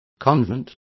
Complete with pronunciation of the translation of convents.